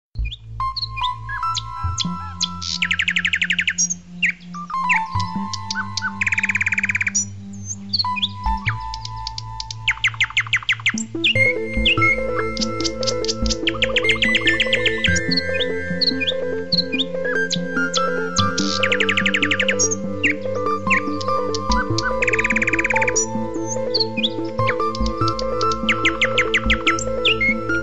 Catégorie Bruitages